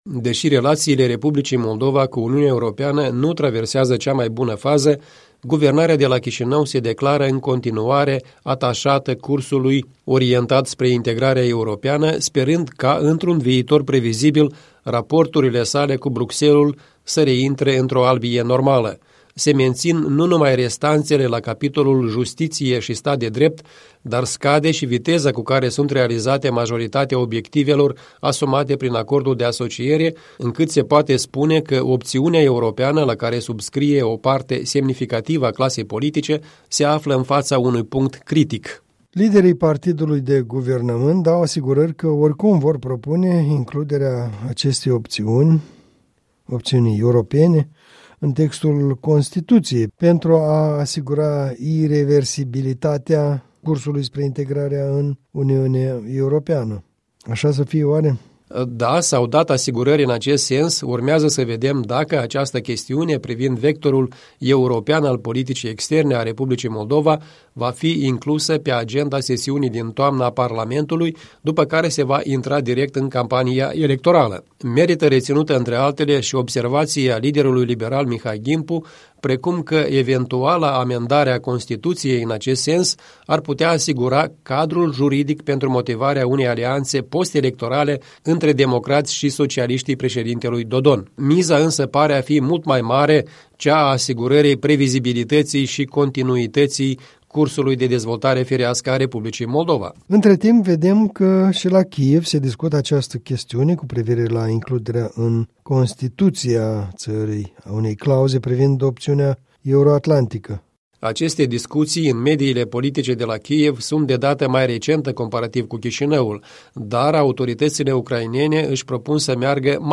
Un punct de vedere săptămânal în dialog despre inițiativa de introducere în legea supremă a obiectivului de integrare europeană.